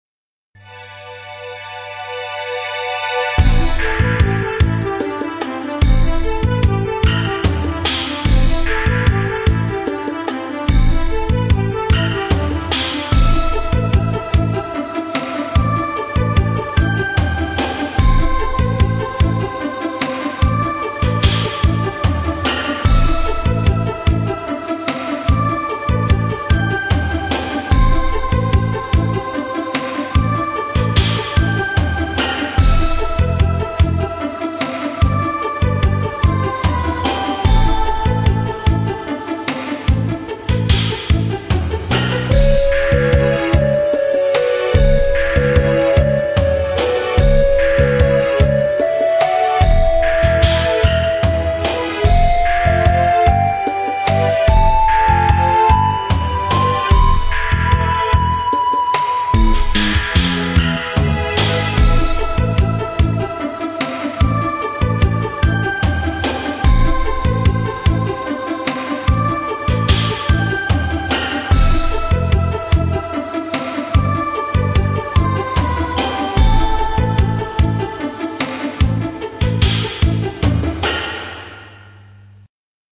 音質下げてます、ご了承下さい。
「不安」シーン。ただ単に不安じゃなくて、あまり落ち着かない様子の不安。 でもバタバタしてる不安じゃなくて、平穏な不安。